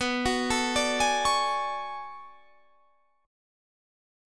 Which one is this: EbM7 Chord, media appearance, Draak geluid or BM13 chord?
BM13 chord